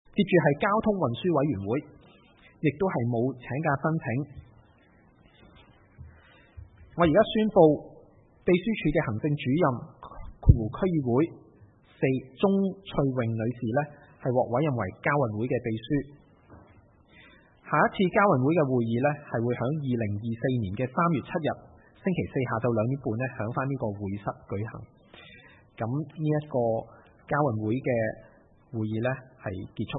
會議的錄音記錄